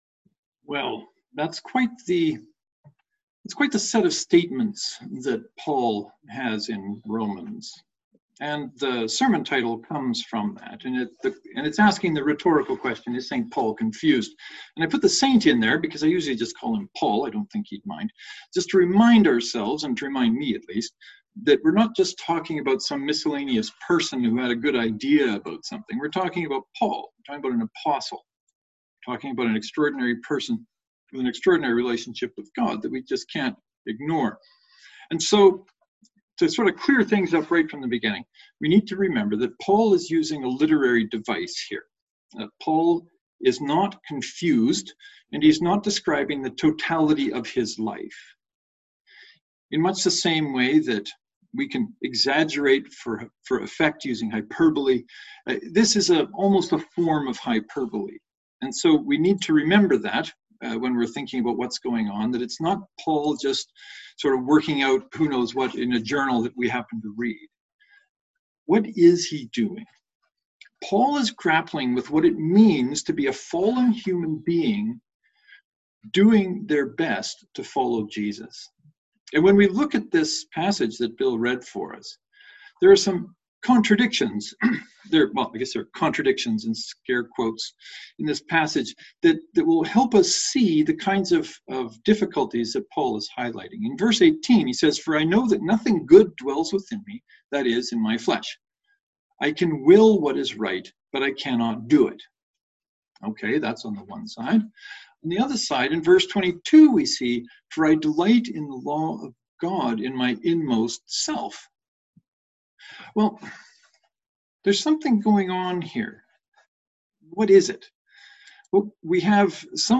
I hope you find something in the sermon to help you with the inevitable burdens we all carry, maybe even the encouragement to put down the burden you don’t want to take up the one made just for you.
Knox and St. Mark’s Presbyterian joint service (to download, right click and select “Save Link As .